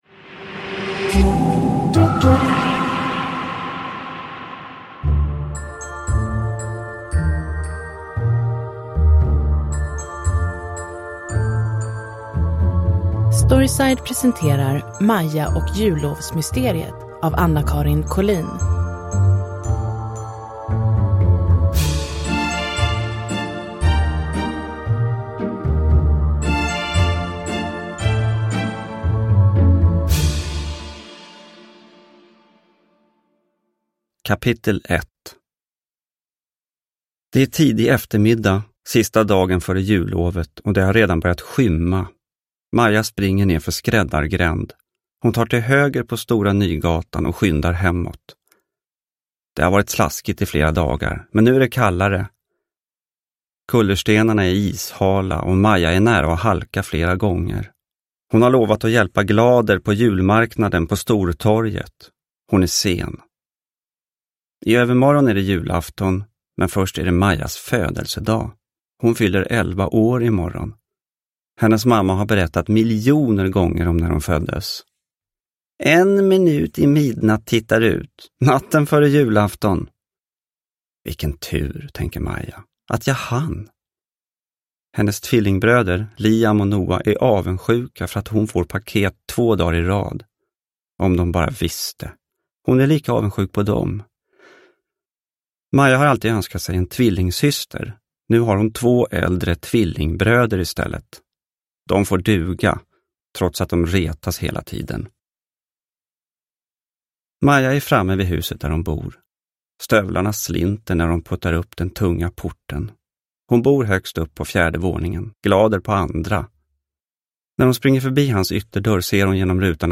Maja och jullovsmysteriet – Ljudbok – Laddas ner
Uppläsare: Gustaf Hammarsten